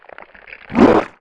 attack_act_1.wav